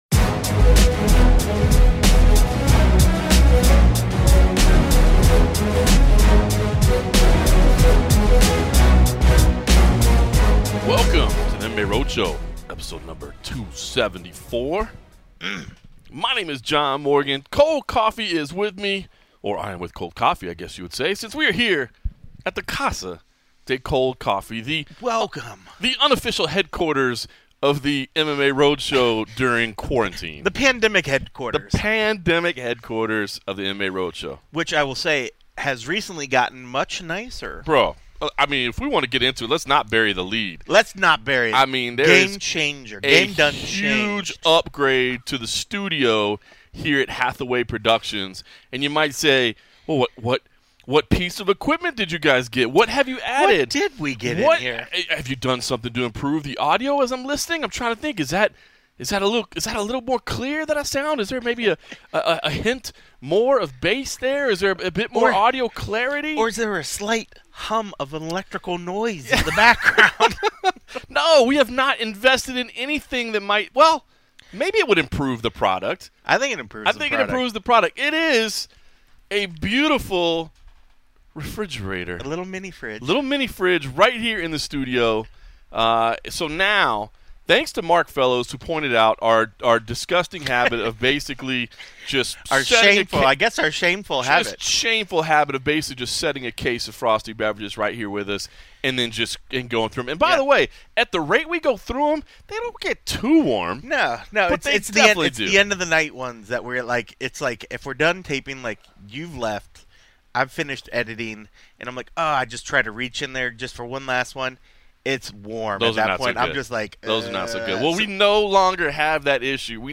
Along the way, listen in on a virtual media day interview with Mike Perry.